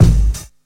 Boom-Bap Kick 55.wav